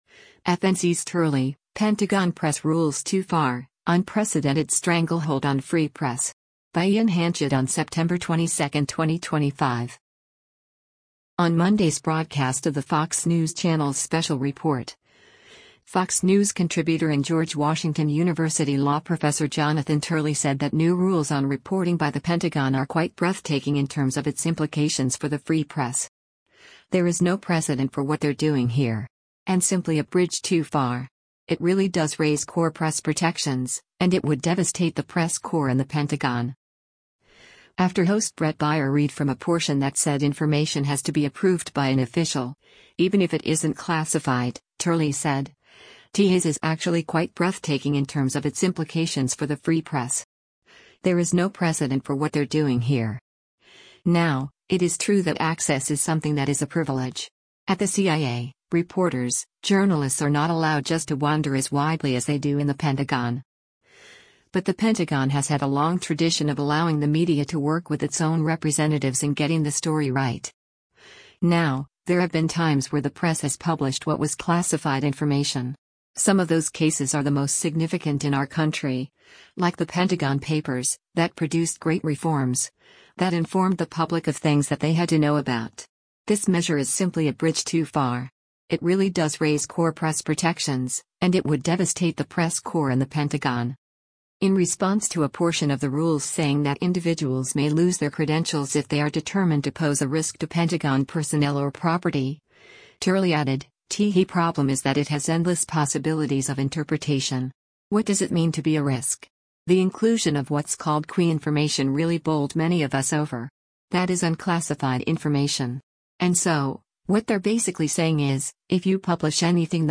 On Monday’s broadcast of the Fox News Channel’s “Special Report,” Fox News Contributor and George Washington University Law Professor Jonathan Turley said that new rules on reporting by the Pentagon are “quite breathtaking in terms of its implications for the free press. There is no precedent for what they’re doing here.” And “simply a bridge too far. It really does raise core press protections, and it would devastate the press corps in the Pentagon.”